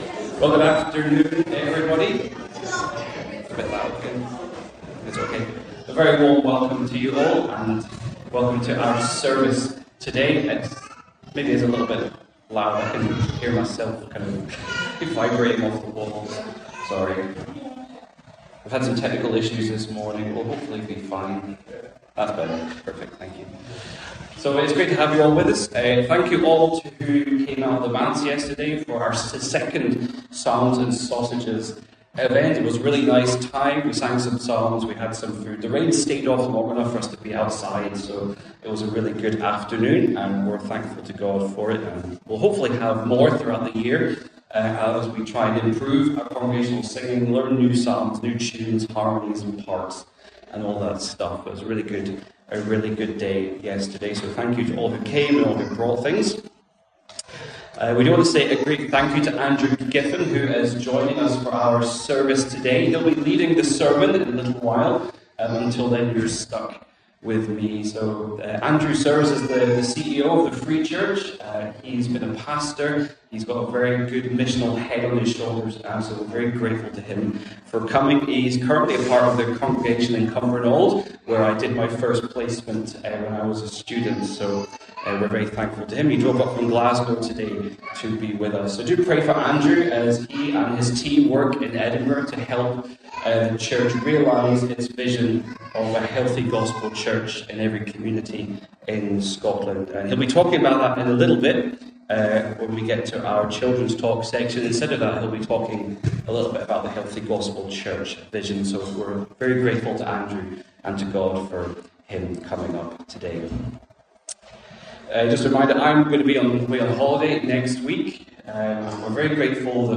12-Noon-Service.mp3